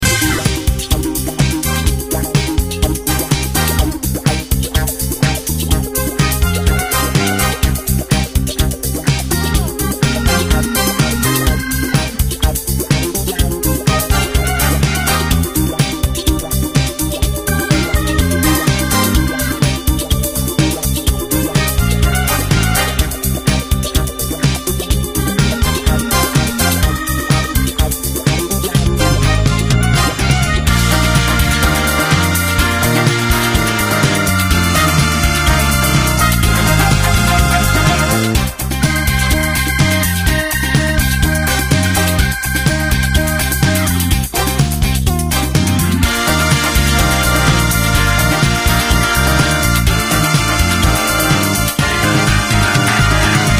Аудиодорожка: Джаз и напряжение
Фоновая музыка напоминает саундтреки к криминальным фильмам.